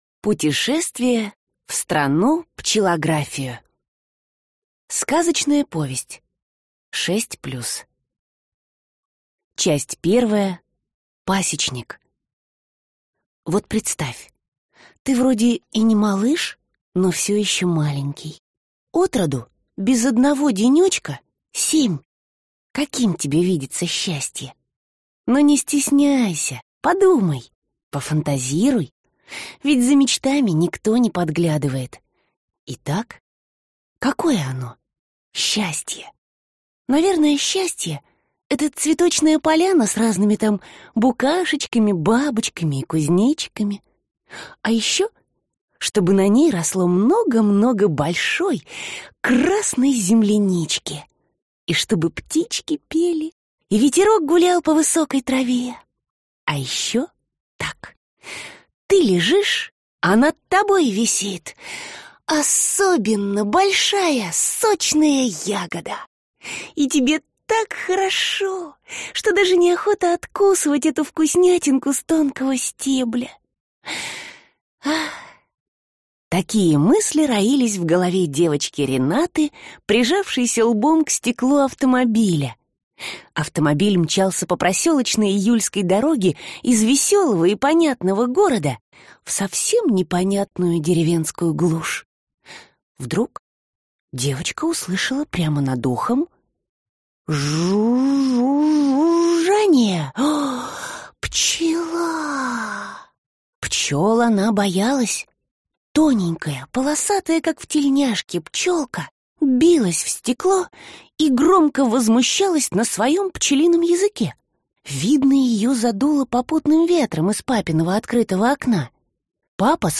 Аудиокнига Пчелография. Крылатое приключение | Библиотека аудиокниг